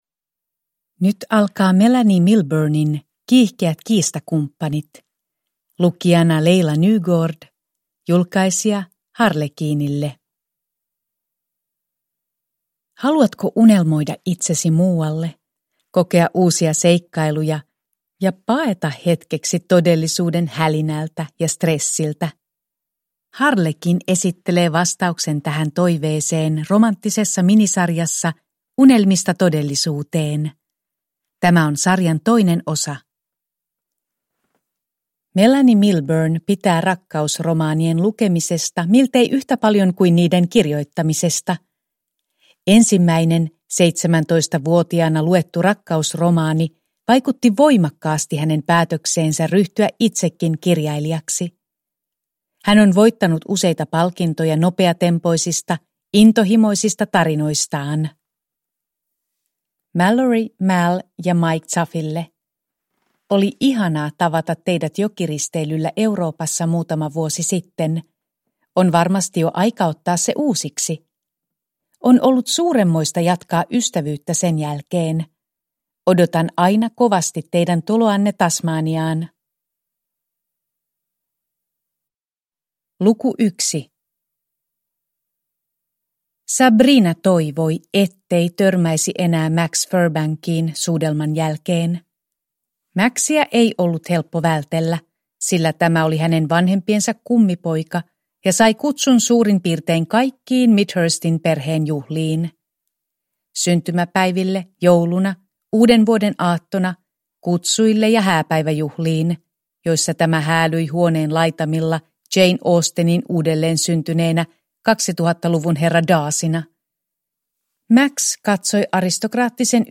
Kiihkeät kiistakumppanit – Ljudbok – Laddas ner